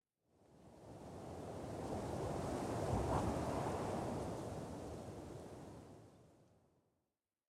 Minecraft Version Minecraft Version latest Latest Release | Latest Snapshot latest / assets / minecraft / sounds / ambient / nether / soulsand_valley / wind4.ogg Compare With Compare With Latest Release | Latest Snapshot
wind4.ogg